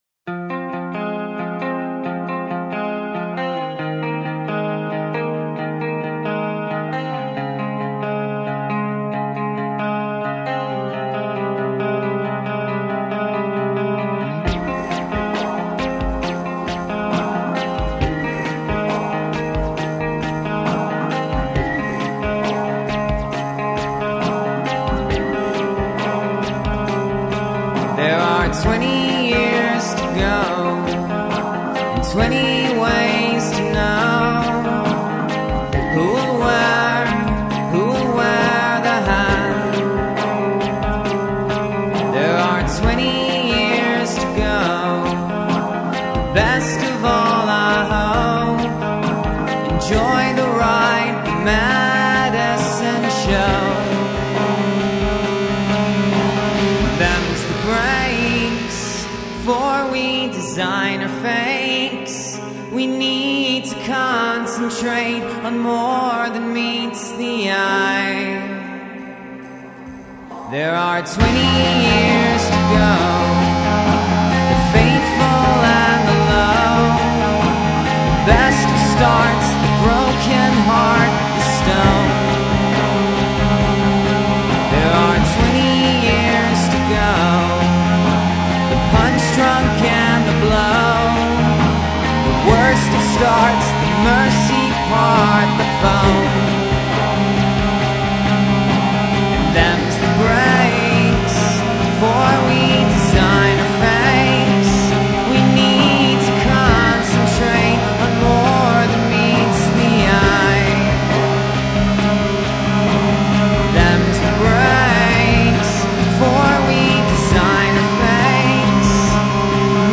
Alternative rock Indie rock